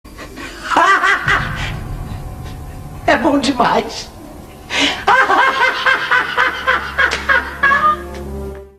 Nazaré Tedesco da novela Senhora do Destino solta uma risada e fala "É bom demais!"
risada-nazare-tedesco-e-bom-demais.mp3